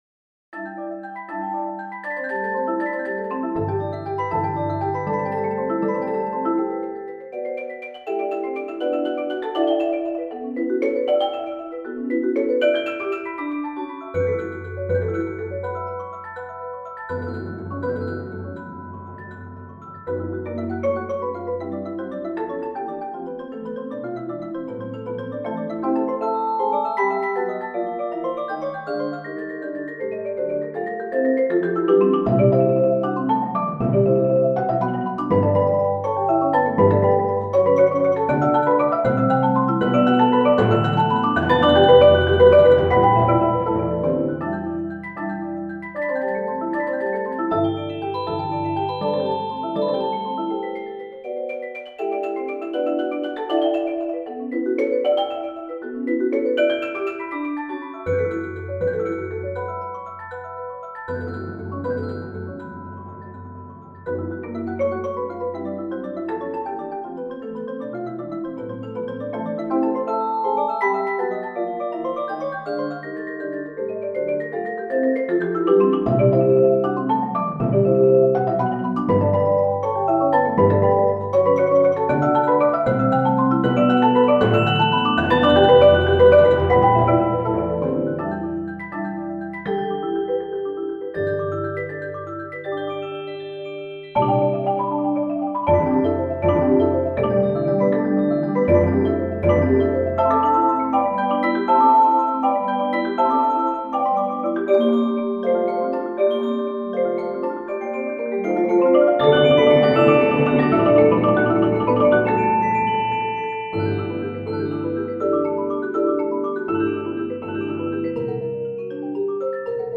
• Personnel: 8 players